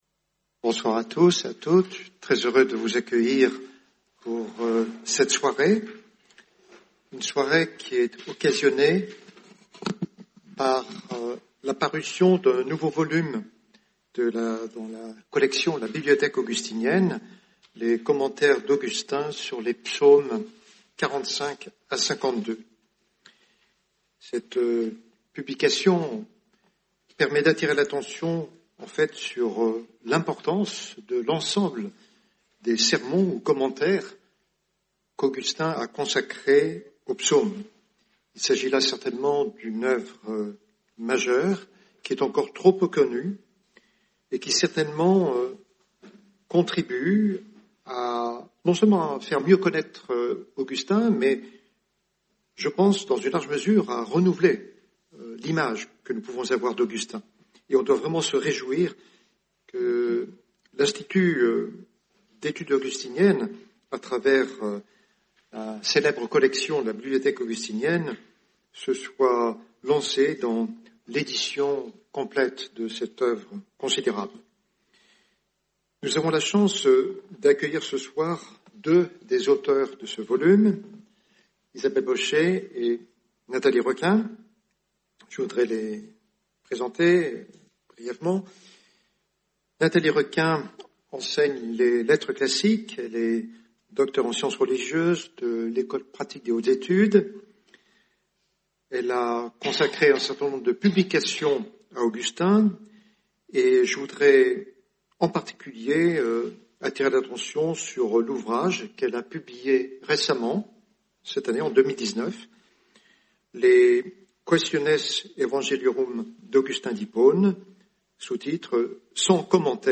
Soirée du 11 octobre 2019